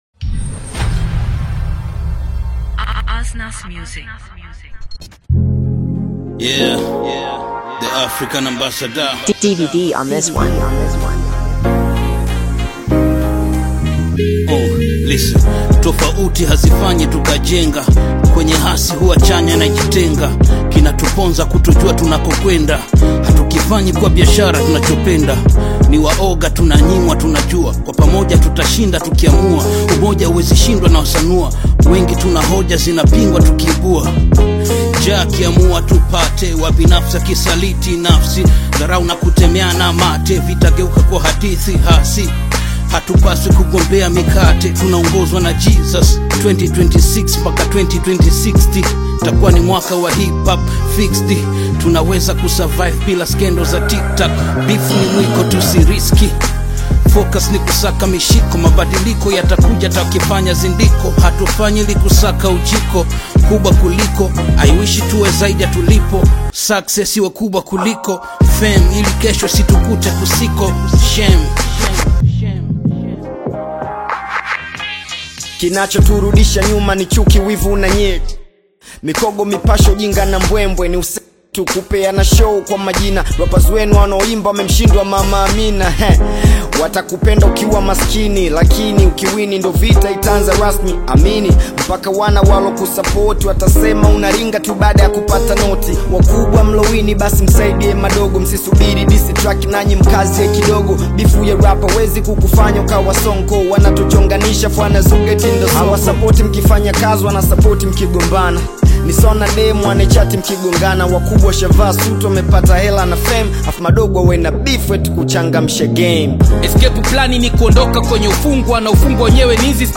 Genre: Afro-Beats, Tanzania Songs